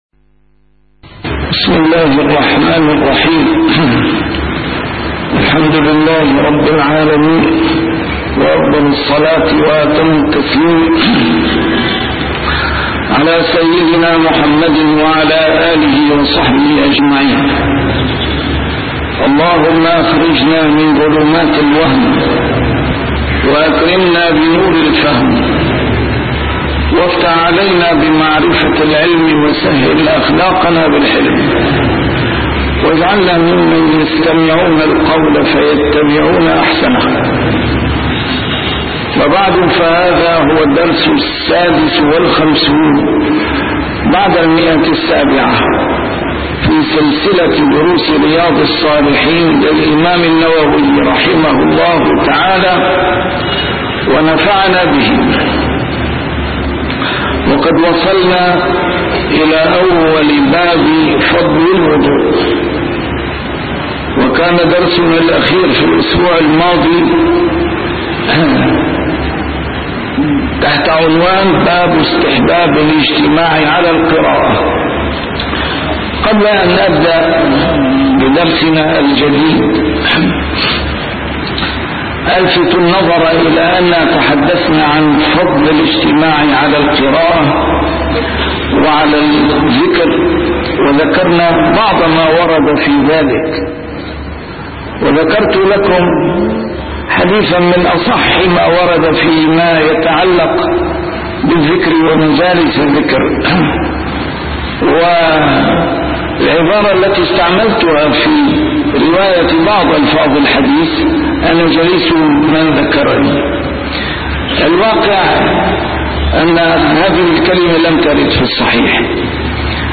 A MARTYR SCHOLAR: IMAM MUHAMMAD SAEED RAMADAN AL-BOUTI - الدروس العلمية - شرح كتاب رياض الصالحين - 756- شرح رياض الصالحين: فضل الوضوء